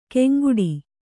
♪ keŋguḍi